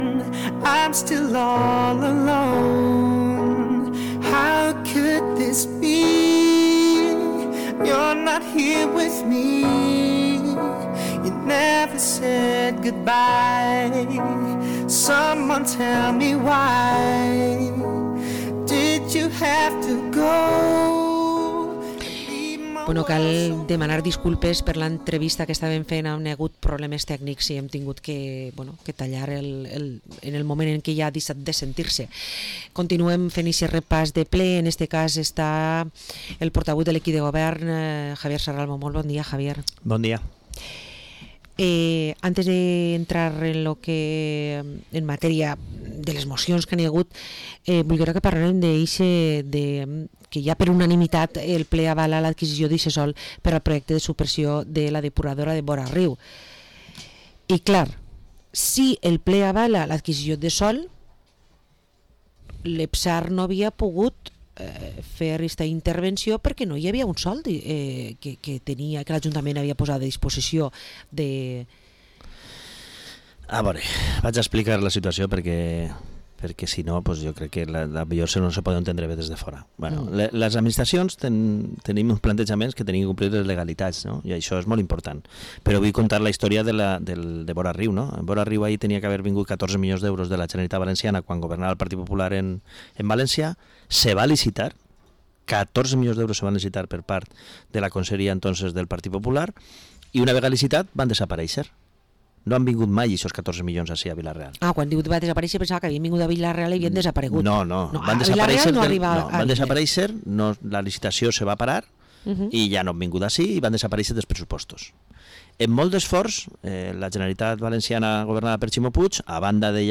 Entrevista al portavoz del equipo de gobierno de Vila-real, Javier Serralvo